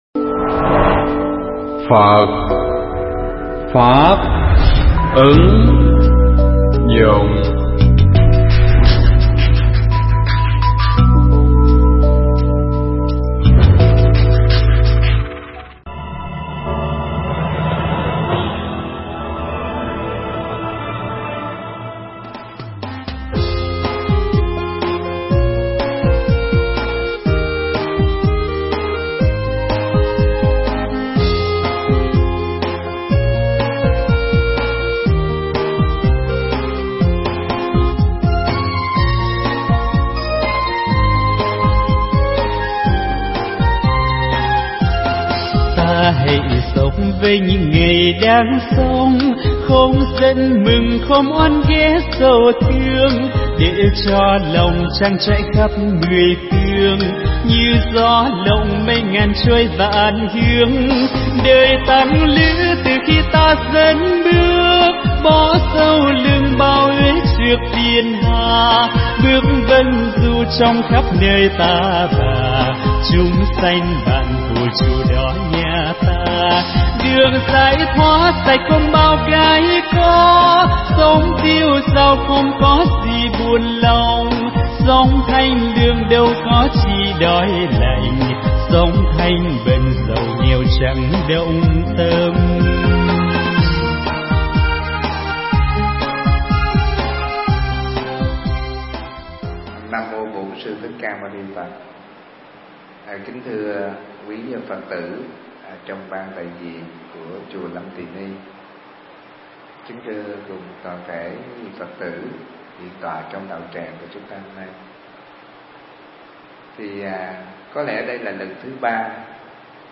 Nghe Mp3 thuyết pháp Hãy Sống Như Hoa Sen
Mp3 pháp thoại Hãy Sống Như Hoa Sen